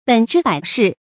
本枝百世 běn zhī bǒi shì 成语解释 指子孙昌盛，百代不衰。